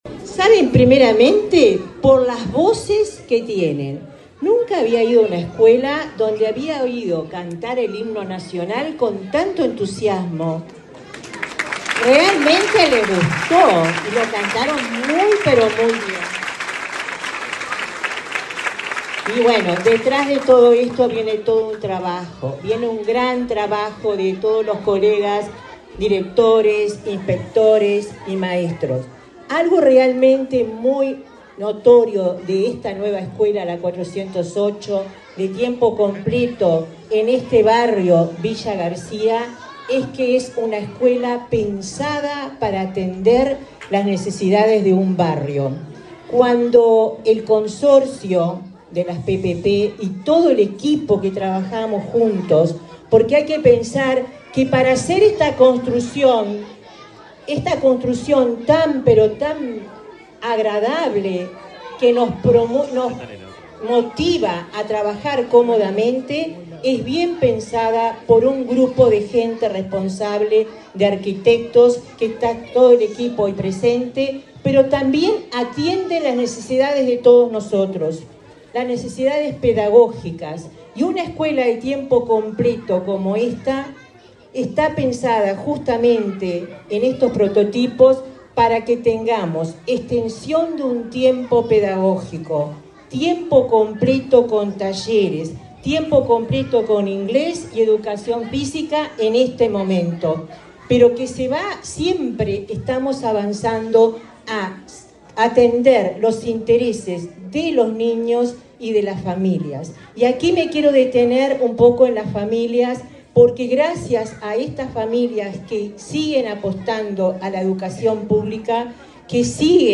Palabras de autoridades de la ANEP
Palabras de autoridades de la ANEP 28/06/2024 Compartir Facebook X Copiar enlace WhatsApp LinkedIn La directora general de Primaria, Olga de las Heras, y la presidenta de la ANEP, Virginia Cáceres, participaron, este viernes 28 en Montevideo, en la inauguración de una escuela de tiempo completo en Villa García.